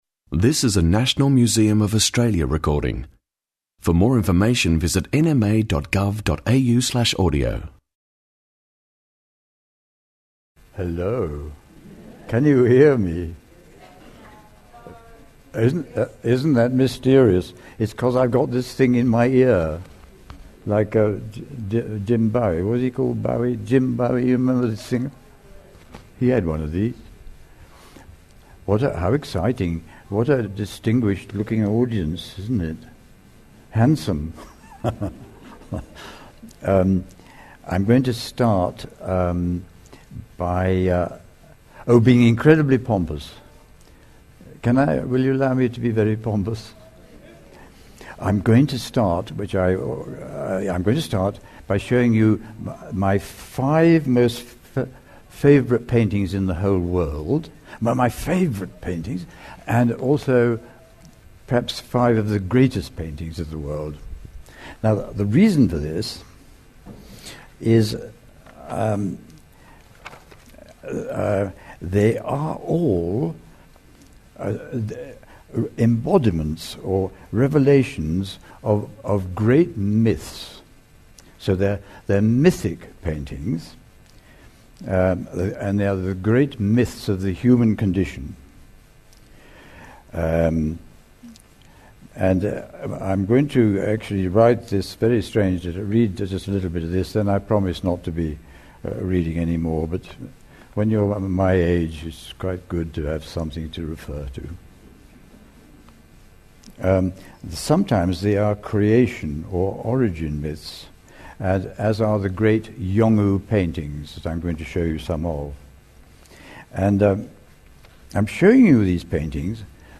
Midawarr (Harvest) artist talk with John Wolseley | National Museum of Australia
Open player in a new tab Presenters: John Wolseley Tags: arts first nations 00:00 / 49:17 Download Midawarr (Harvest) artist talk with John Wolseley audio file (22.6 MB) View transcript